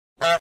honk.mp3